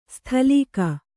♪ sthalīka